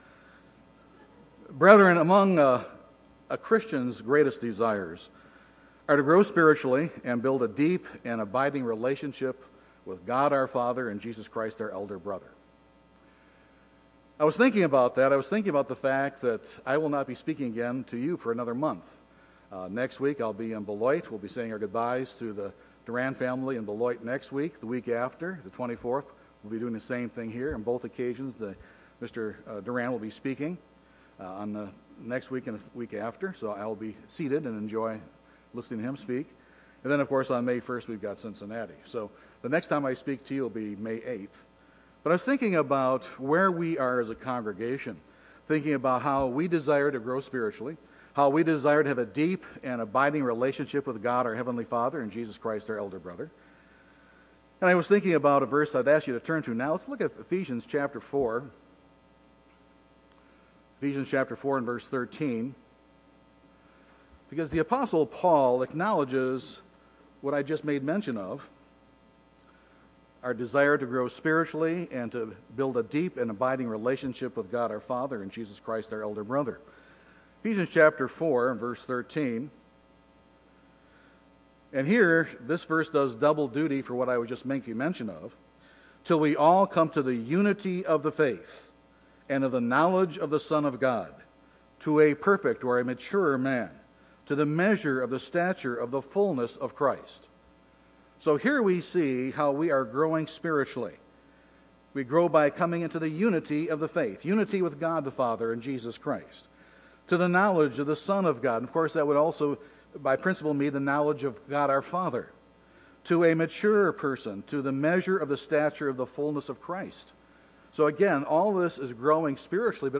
This sermon will examine a specific case of a "not now" answer.